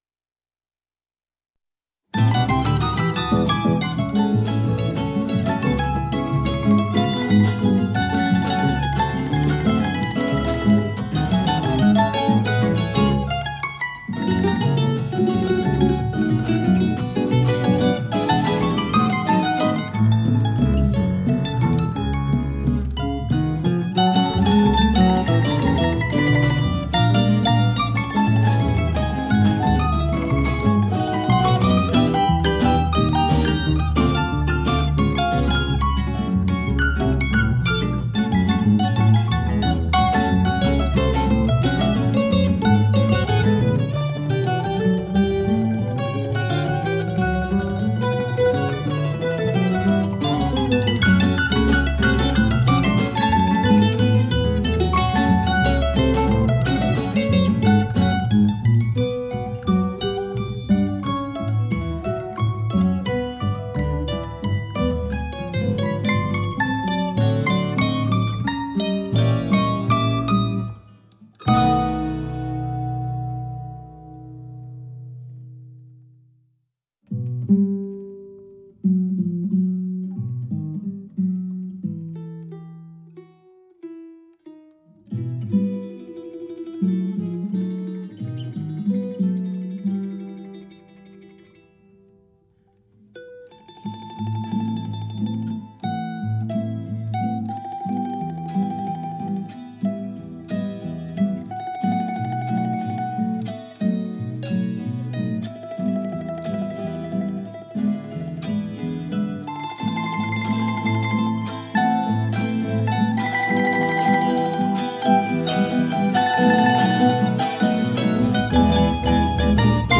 Bambuco